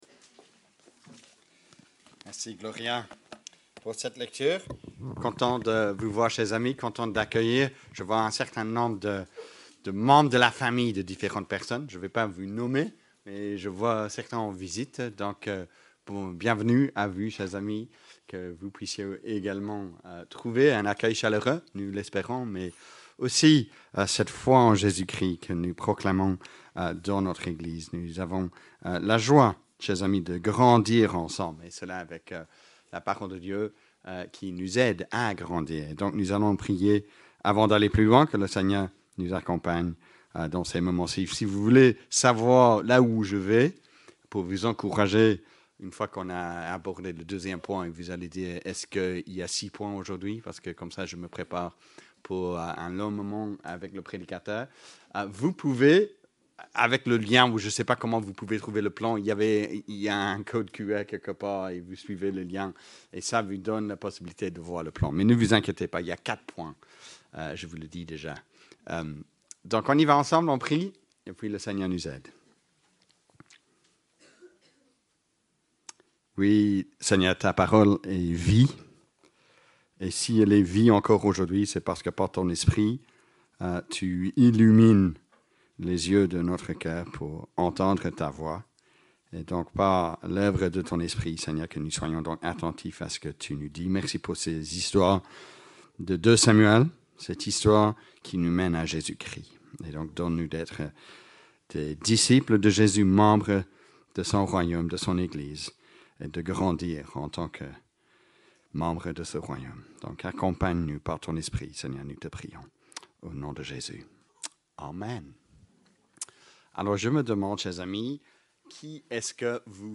Predication_12-février_audio.mp3